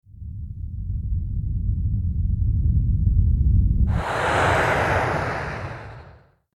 rocket_launch.mp3